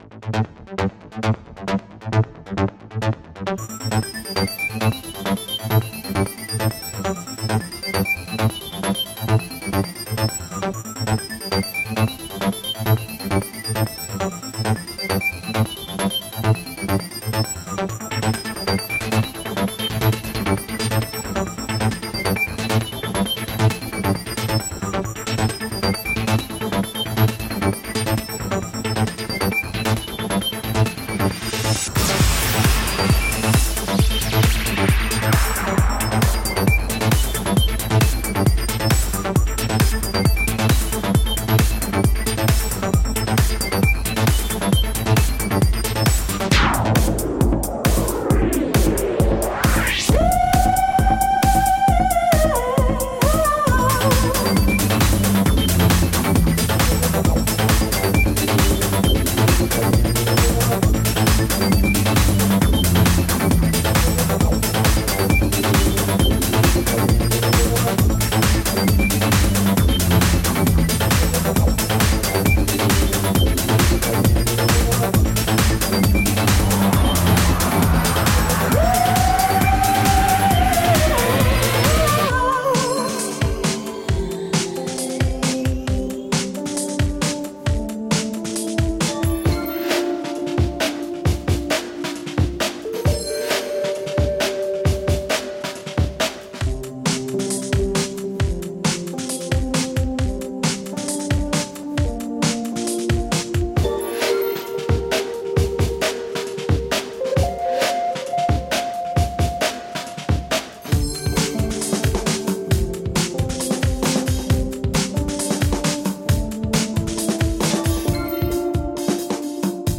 Electronica with a heart.